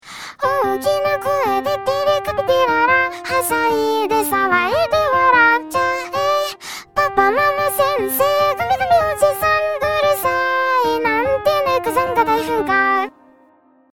音色を増やしすぎると不協和音になる？なんか気持ち悪い...
ピアノはLogic Pro, ボーカルはCeVio AIから出力した